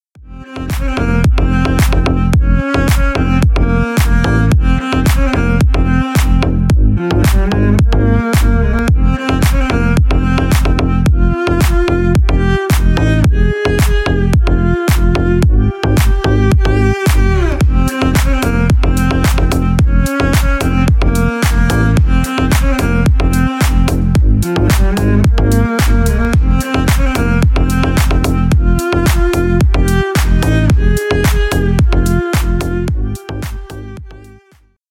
Танцевальные
без слов
клубные # восточные